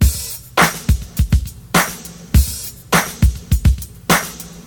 Free drum loop - kick tuned to the C# note. Loudest frequency: 2175Hz
• 103 Bpm Drum Groove C# Key.wav
103-bpm-drum-groove-c-sharp-key-n3s.wav